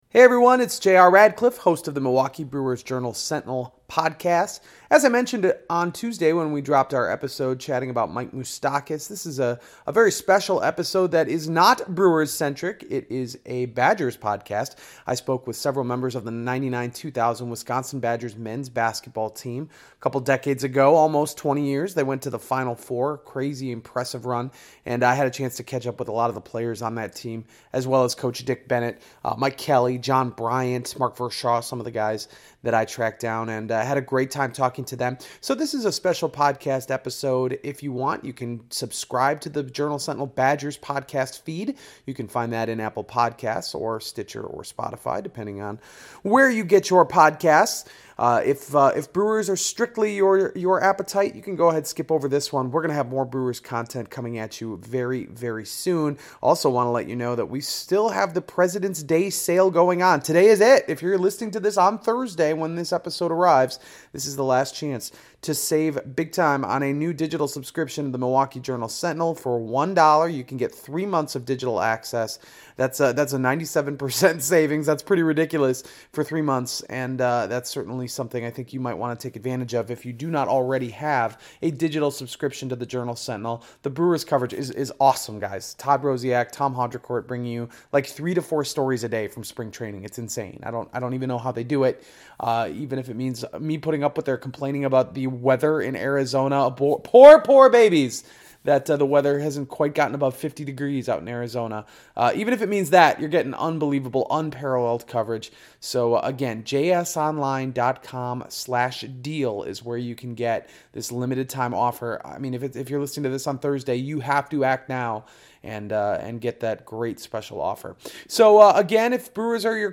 Audio clips from NCAA on YouTube.